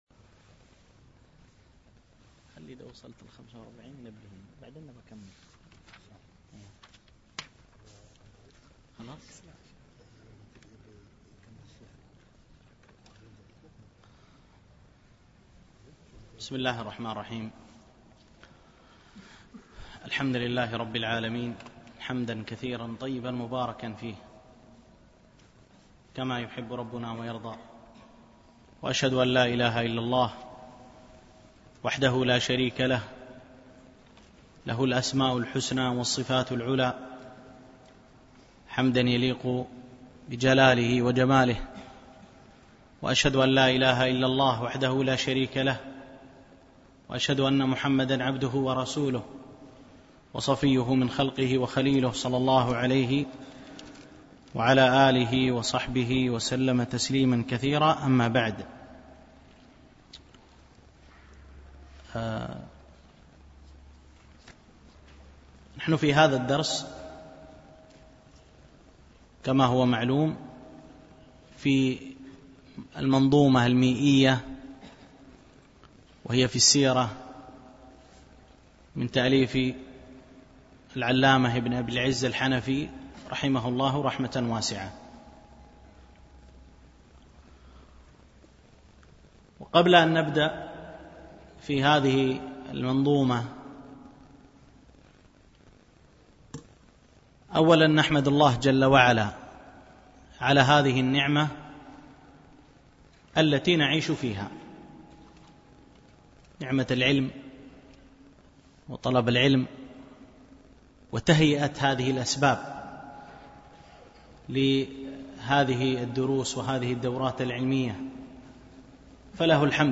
الألبوم: دروس مسجد عائشة (برعاية مركز رياض الصالحين ـ بدبي)